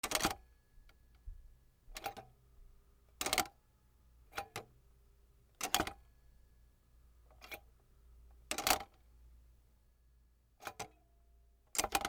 / G｜音を出すもの / G-01 機器_電話
電話(レトロ) 受話器を置く
ガチャン